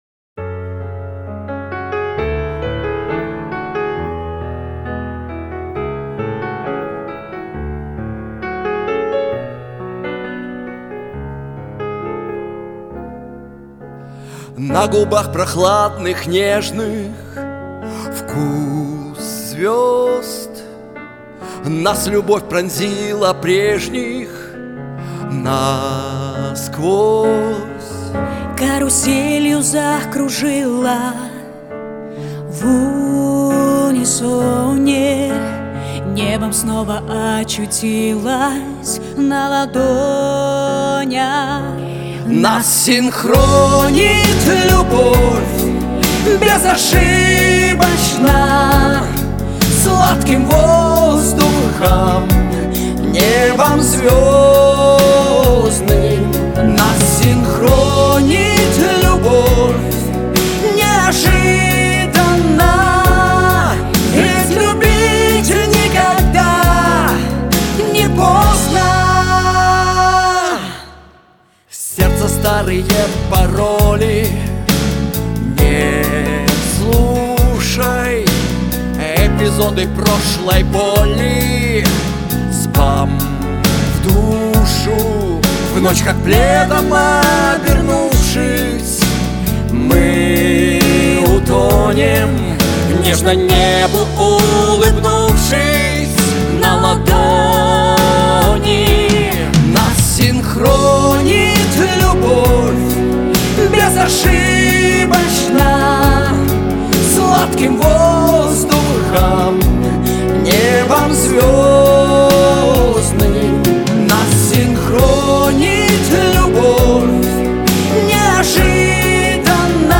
дуэт
эстрада
Шансон
Лирика